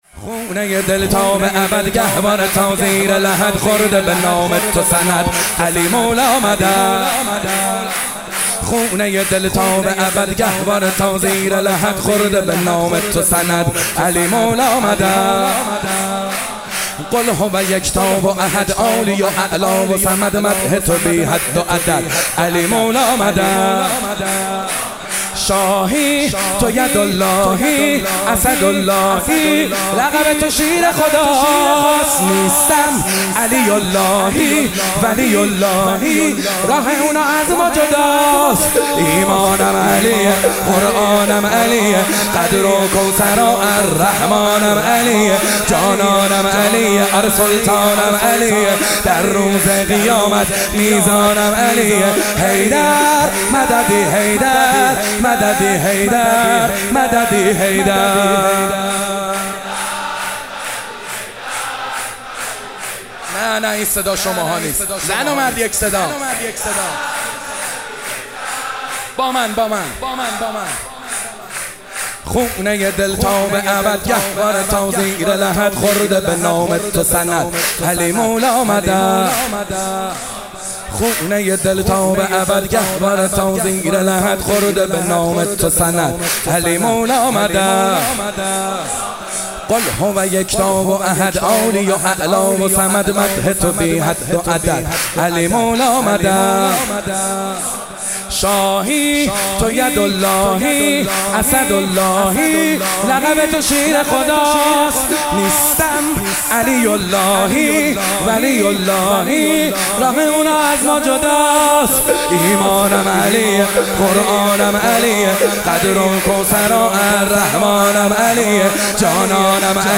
ولادت حضرت محمد(ص)امام جعفرصادق(ع)97 شور محمد حسین حدادیان